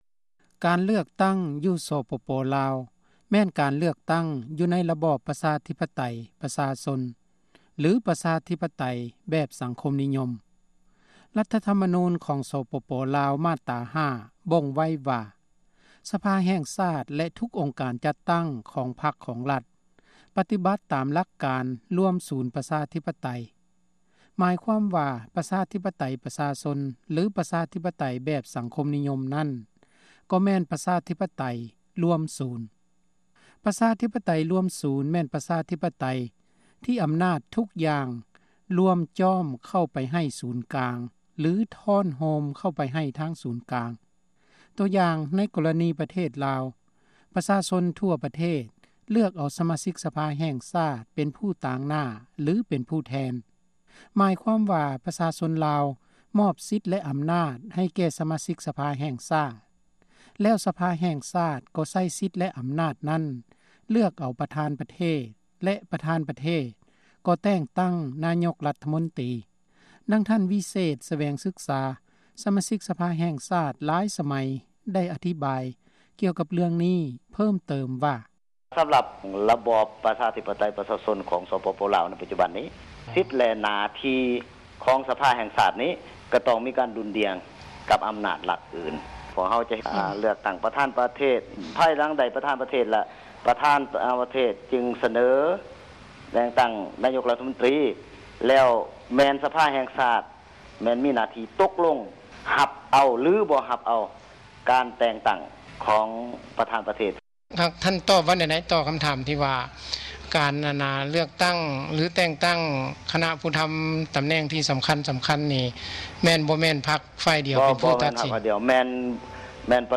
ບົດວິເຄາະ